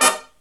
HIGH HIT17-R.wav